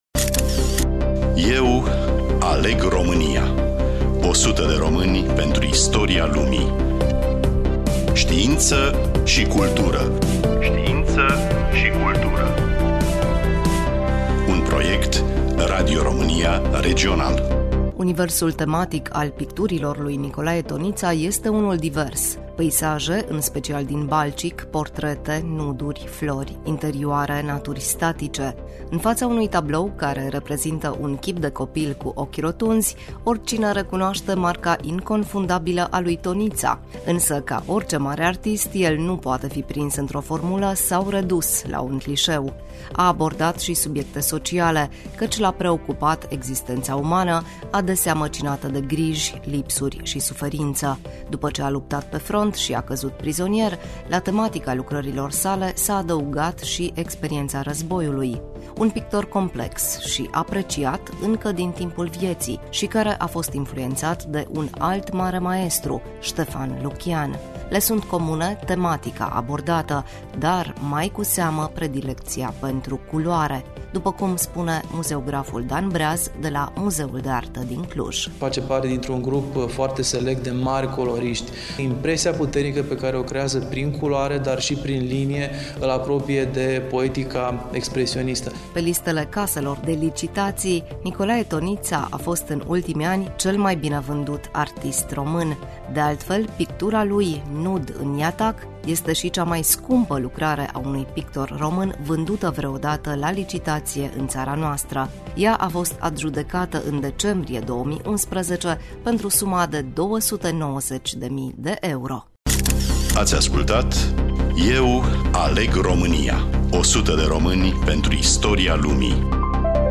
Studioul: Radio Romania Cluj